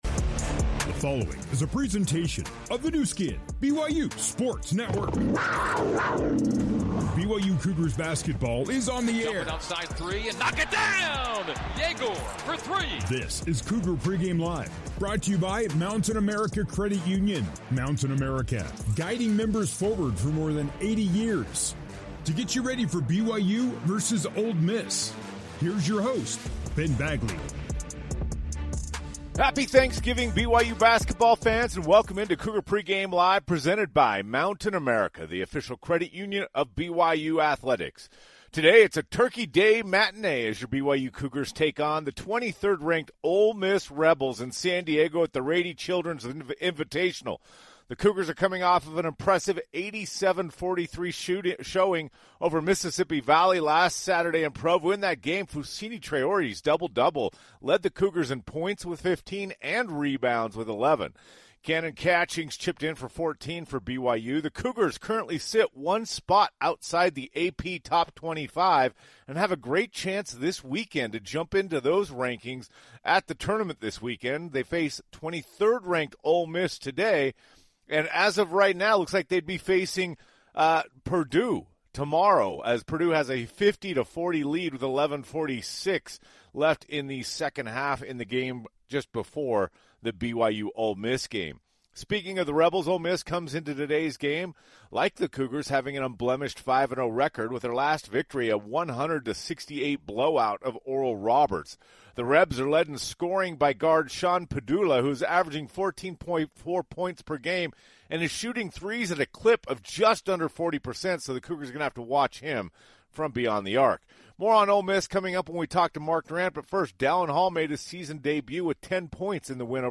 BYU at Ole Miss Full Broadcast